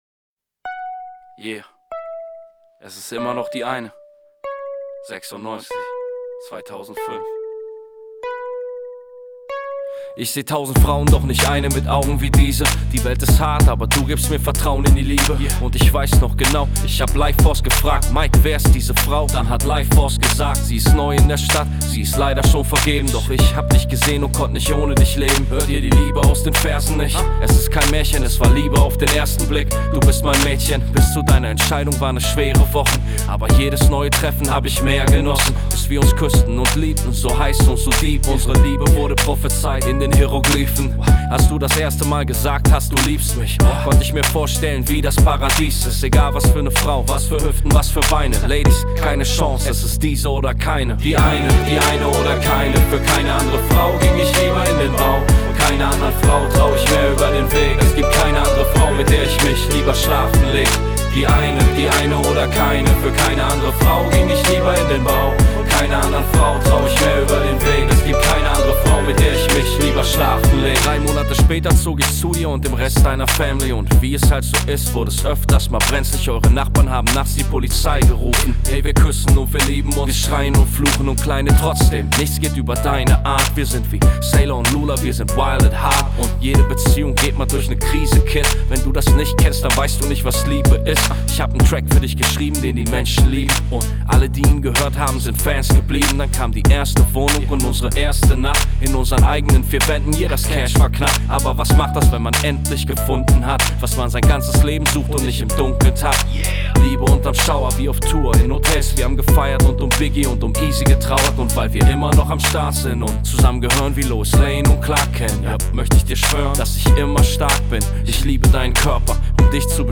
Pop GER